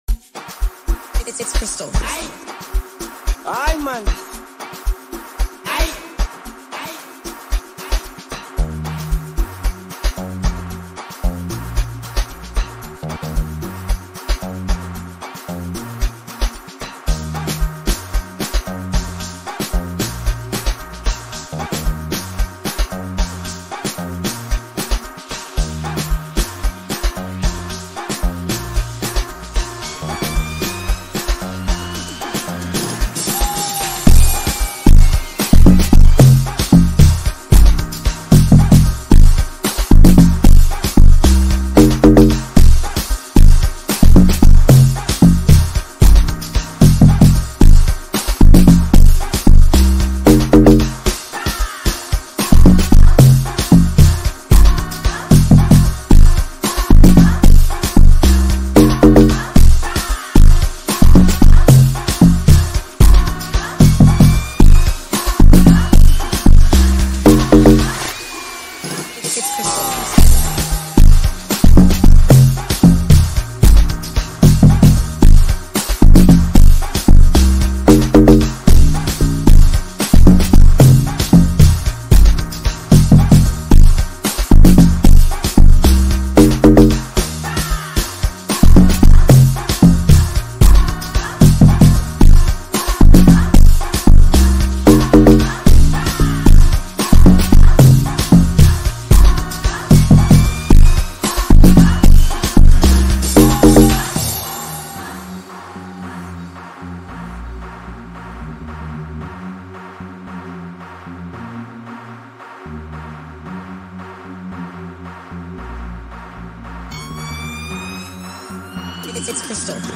Home » Amapiano » DJ Mix » Hip Hop